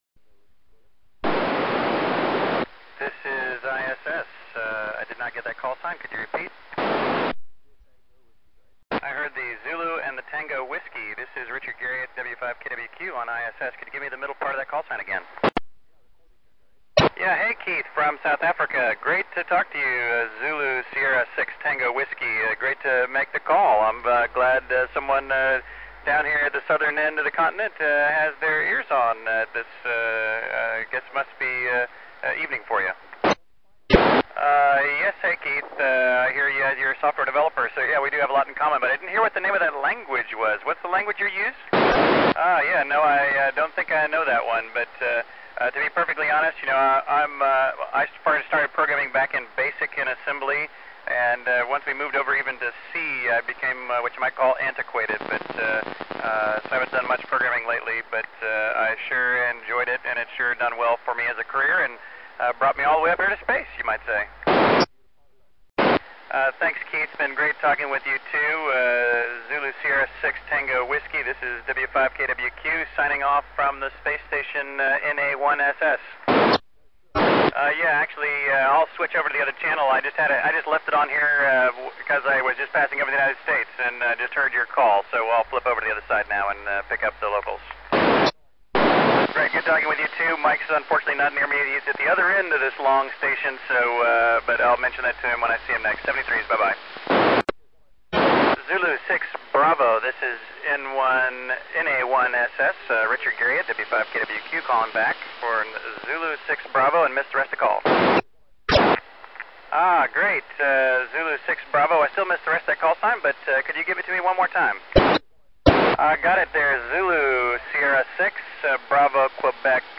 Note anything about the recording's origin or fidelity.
The signal did not appear as super strong (i.e. 59++) as always from ISS - explained by the fact that I somehow had been switched to the vertical instead of the yagis!